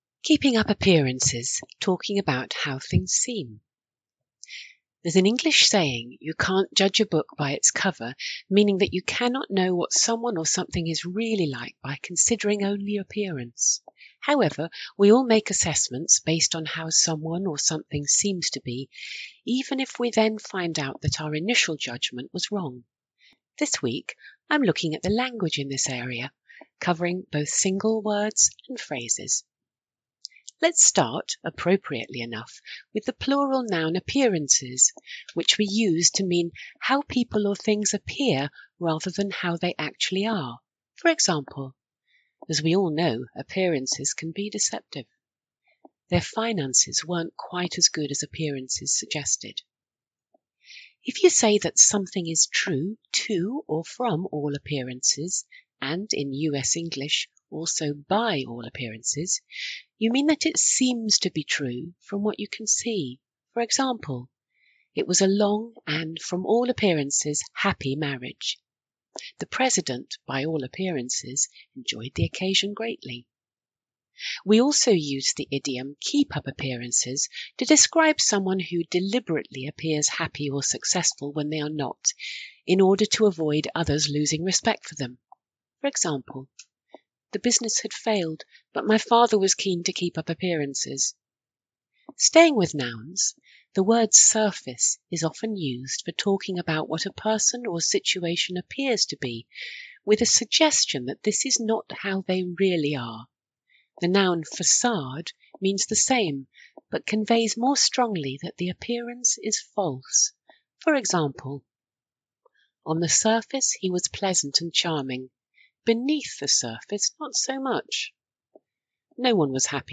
Listen to the author reading this blog post: